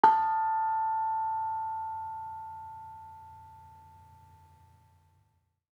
HSS-Gamelan-1
Bonang-A4-f.wav